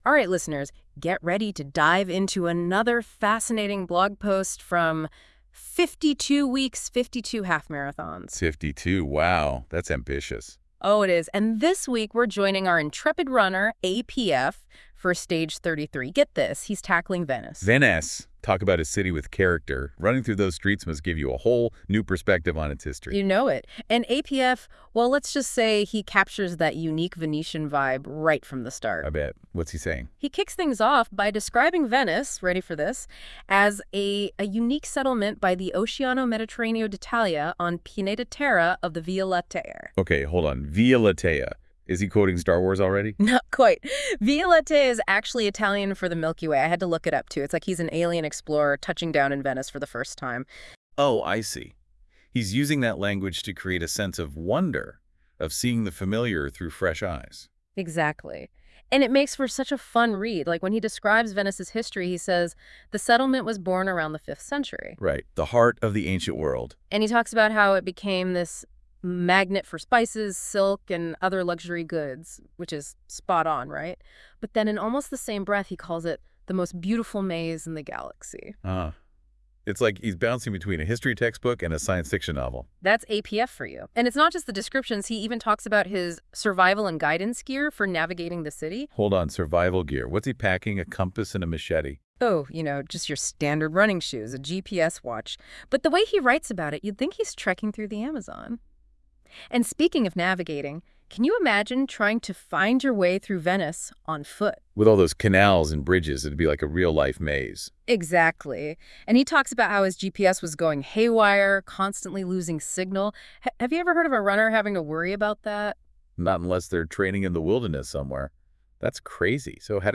• Move Google AI Generated podcasts